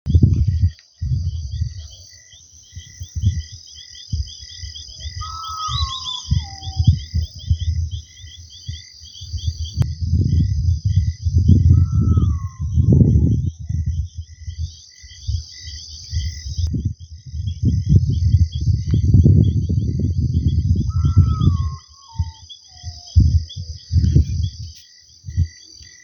Urutaú Común (Nyctibius griseus)
Nombre en inglés: Common Potoo
Localización detallada: 5 km al Este de Las Varillas - Estancia Las Varillas
Condición: Silvestre
Certeza: Observada, Vocalización Grabada